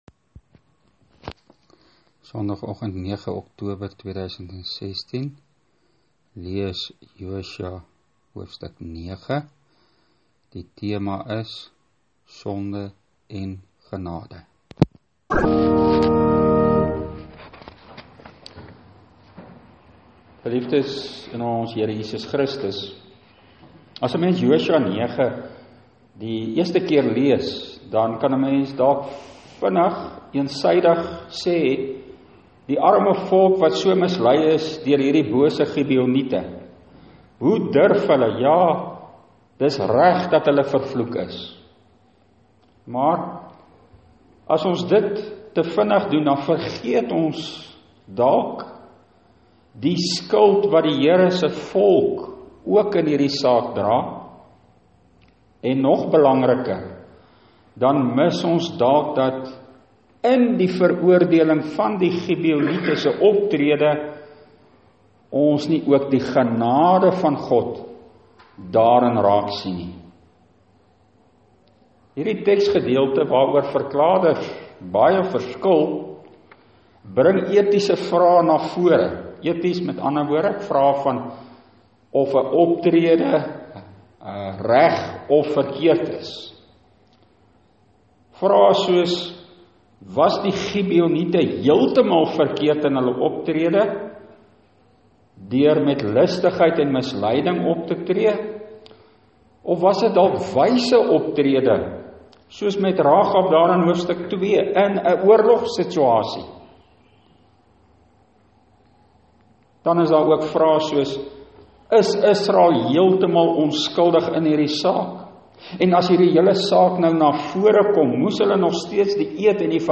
Preekopname (GK Carletonville, 2016-10-09):